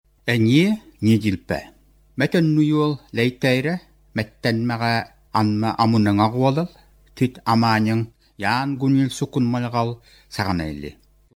Тексты представляют собой естественные нарративы, записанные в текстовом виде и позже озвученные другим человеком (аудио к некоторым отрывкам прилагаются).
lang_yukagiry_north_all_audio004.mp3